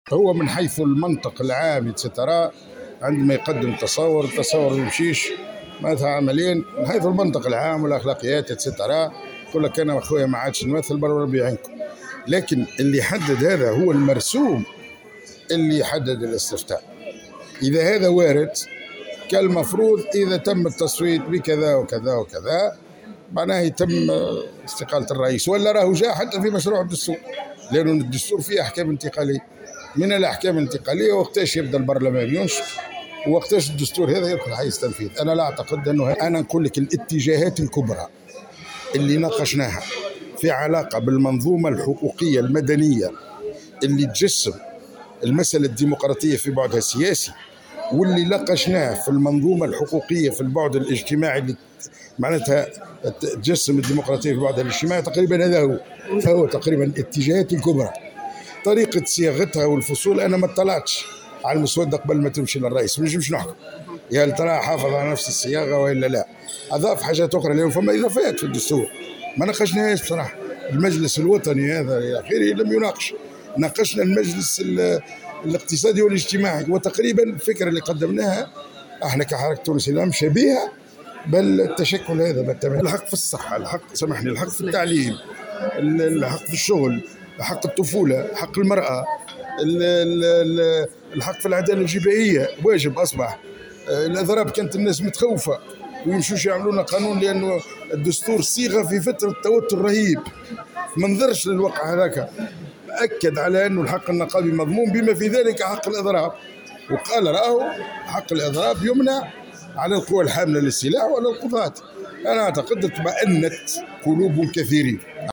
واستبعد البريكي، في تصريح لمراسلة الجوهرة أف أم، على هامش انعقاد مجلس مركزي استثنائي للحركة بعد ظهر اليوم الجمعة بمدينة الحمامات، استقالة رئيس الجمهورية، قيس سعيد، في صورة فشل الاستفتاء، باعتبار أنه لم يتم تضمين ذلك في نص الاستفتاء أو الدستور الجديد.